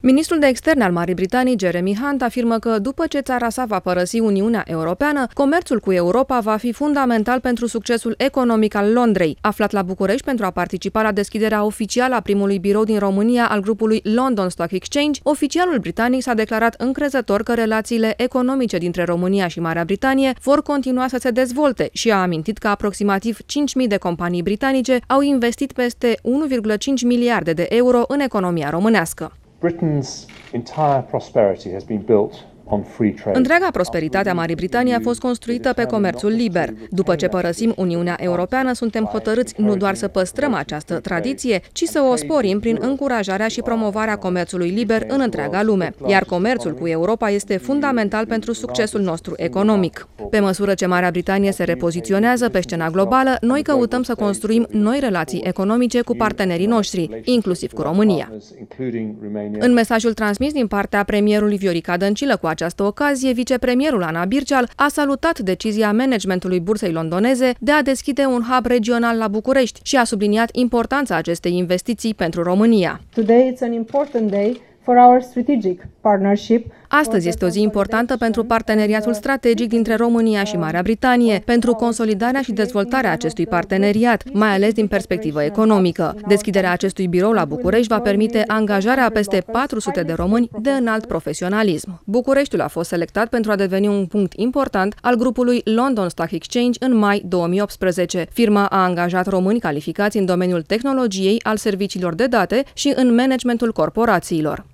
Marea Britanie este hotărâtă, după ce va părăsi Uniunea Europeană, să promoveze liberul schimb şi să creeze noi relaţii cu partenerii, inclusiv România. Este declaraţia făcută astăzi la Bucureşti de ministrul britanic de Externe, Jeremy Hunt, la deschiderea primului birou din România al Grupului London Stock Exchange.